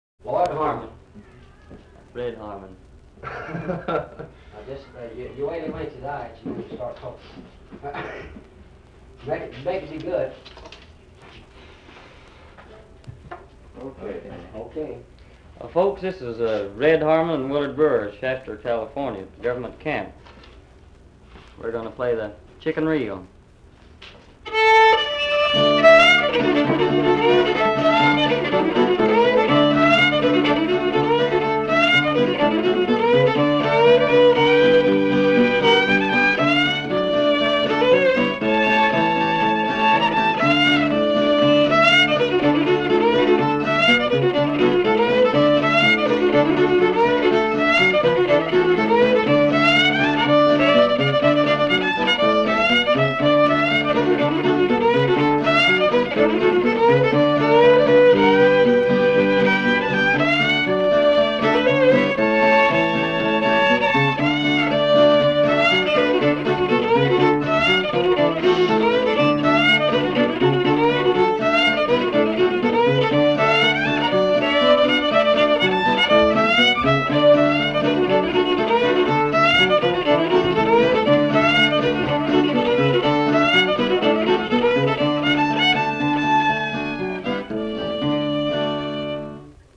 方塊舞(Square Dancing)
guitar
fiddle.